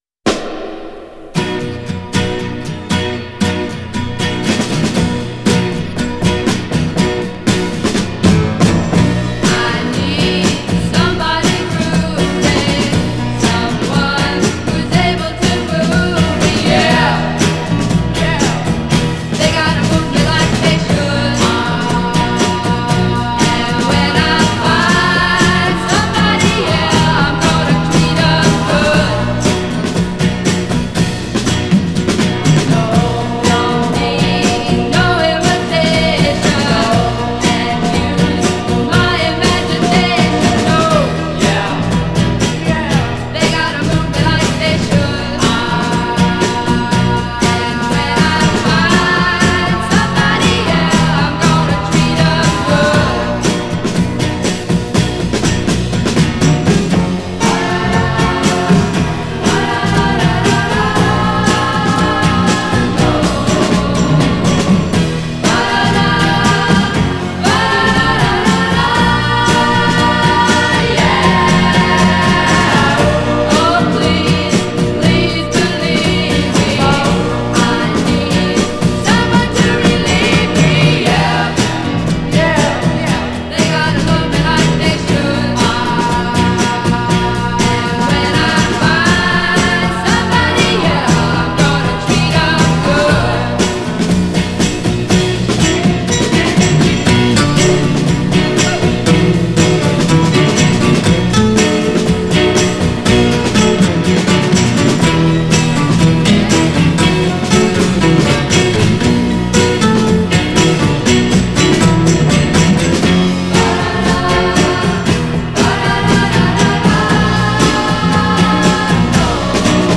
The hippy dream sang beautifully.